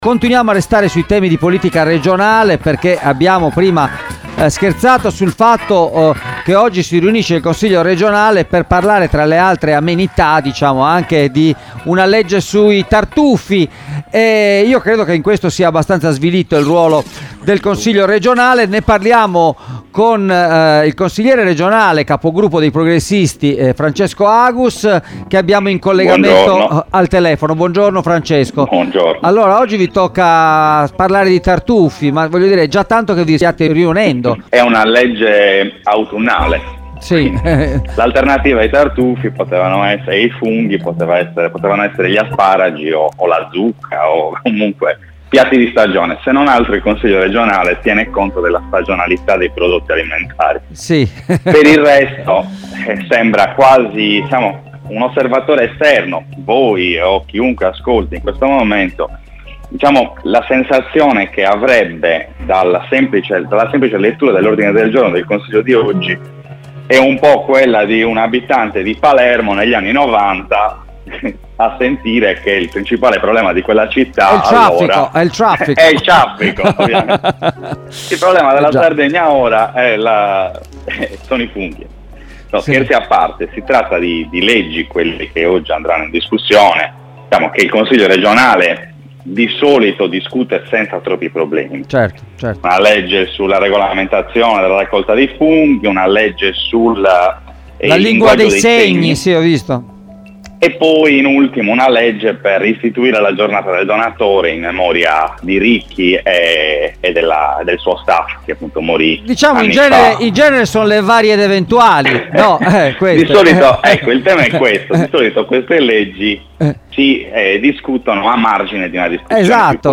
Una politica regionale che dà la precedenza a provvedimenti che normalmente sarebbero ai margini della discussione, per evitare di confrontarsi sui temi veramente importanti come continuità territoriale, caro bollette, aiuti alle aziende e PNRR, i cui fondi destinati alla sanità sono oggi a rischio a causa di una mancata delibera riguardante il patrimonio immobiliare che dalla soppressa Ats, deve transitare nelle ASL. Francesco Agus, portavoce dei Progressisti in Consiglio regionale è intervenuto questa mattina ai microfoni di Extralive a ridosso della riunione convocata per votare la legge ch